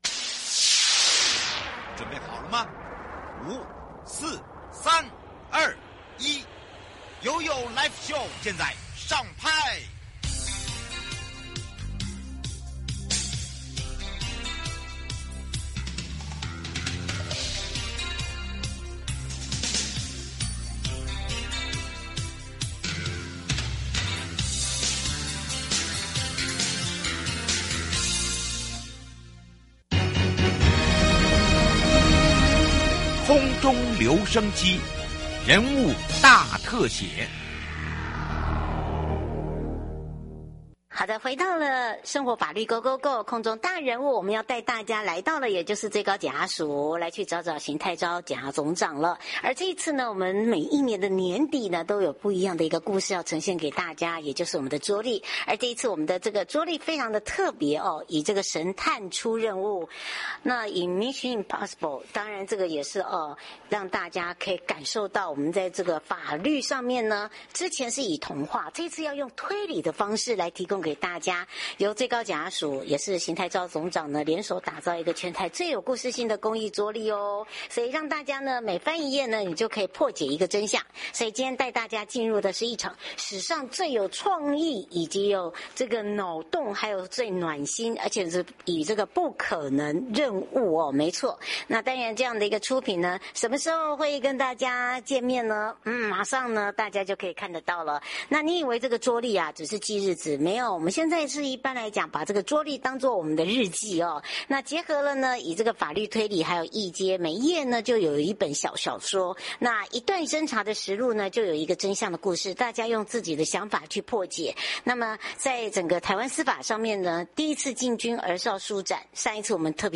受訪者： 最高檢察署邢泰釗檢察總長 節目內容： 主題：逃兵類型大揭密 最高檢下令嚴辦速查求重刑最高檢表示逃避兵